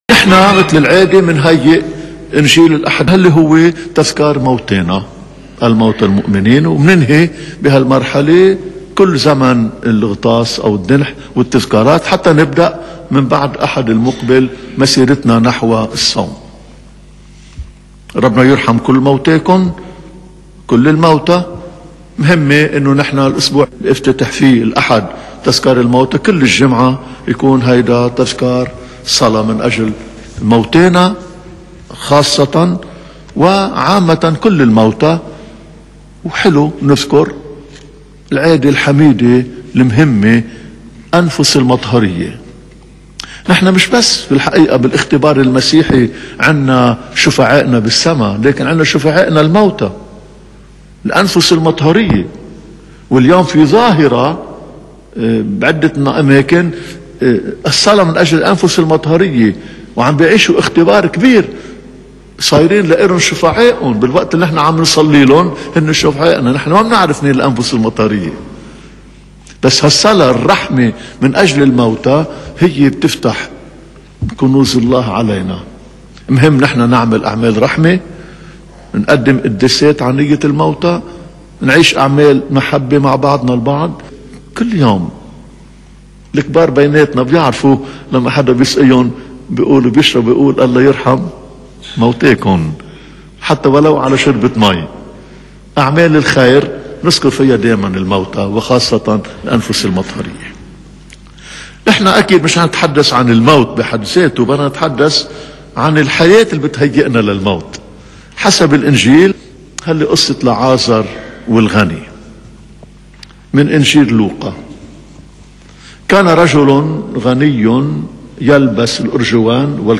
لقد جمعت للمؤمنين ما يشرح معاني هذه المناسبة، إضافة إلى شرح مفصل عن مفهوم ومعاني هذه اليوم كنسياً بالصوت للمطران بشارة الراعي مدته 50 دقيقة مأخوذ من إذاعة صوت المحبة وأذيع اليوم .